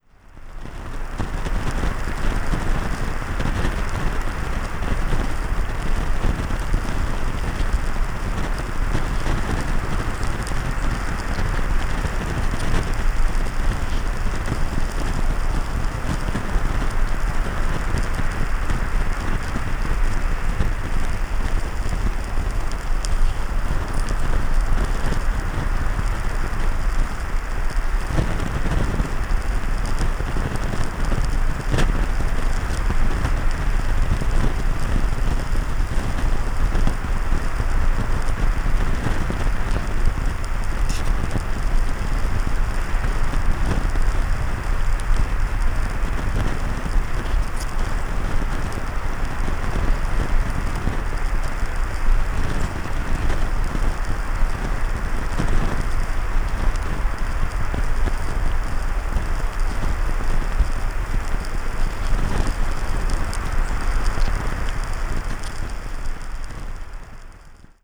Water on the sand
Sonido de el agua corriendo encima de la arena. Grabado con hidrófono.
[ENG] Sound of water running in the sand. Recorded with hidrophone.